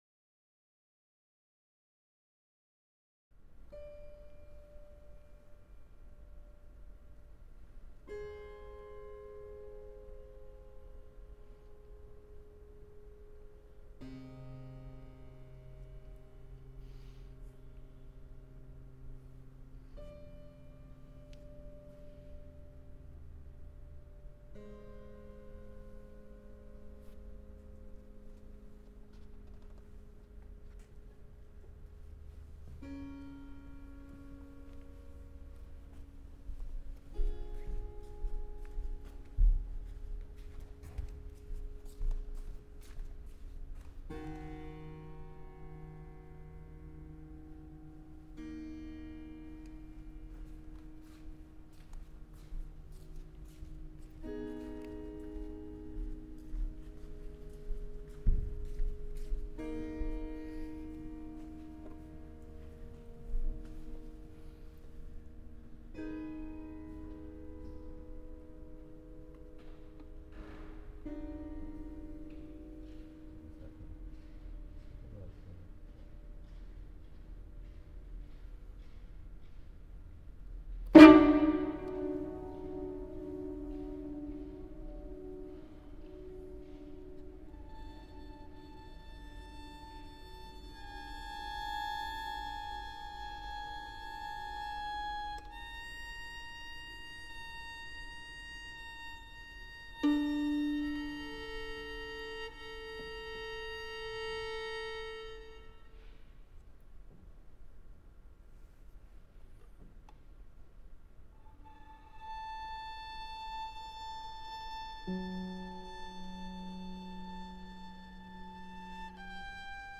Violin
Piano
Concert day – rehearsal recordings